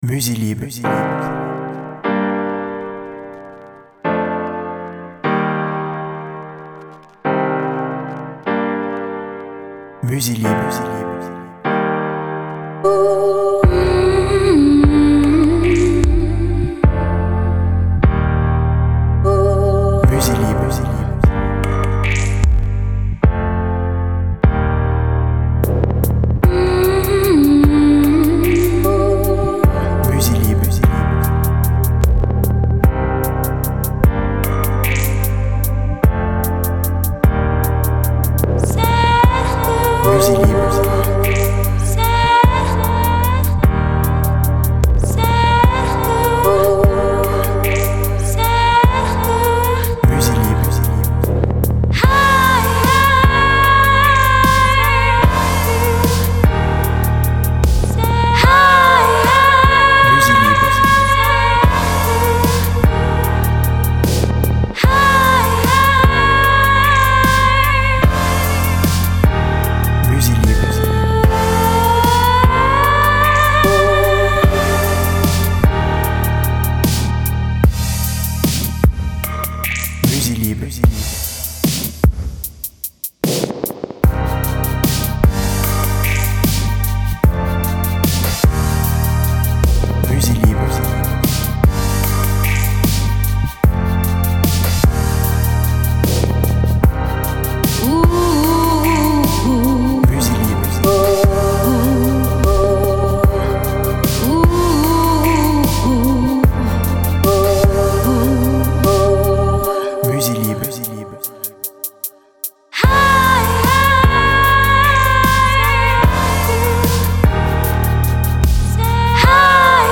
BPM Lent